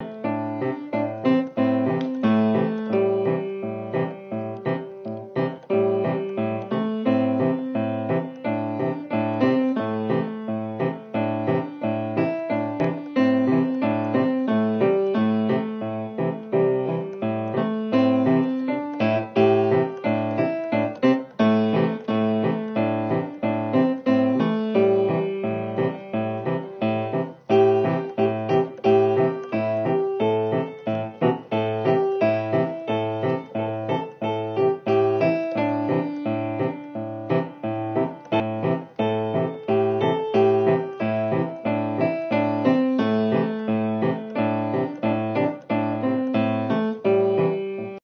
用一架长期不用的“走音”钢琴
优美的曲子在大厅内回响
观众席上掌声雷动